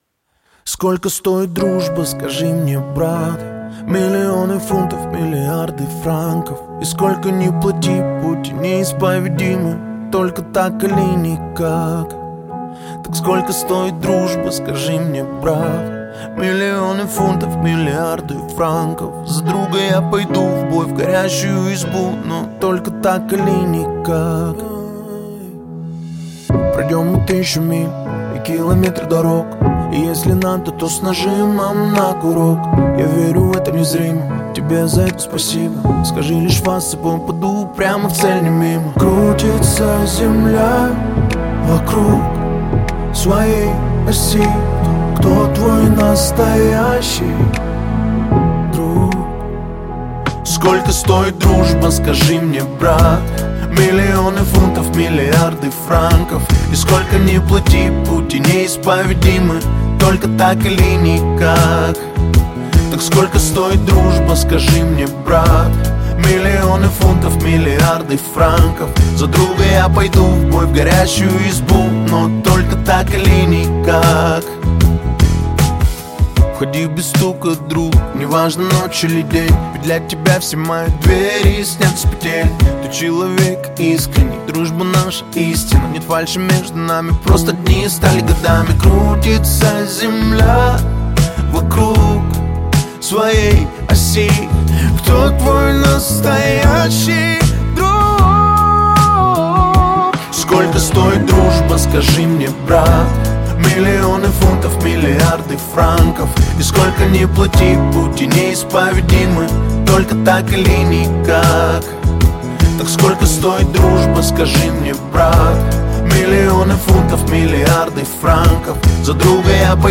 Поп-музыка
Жанр: Поп-музыка / Саундтреки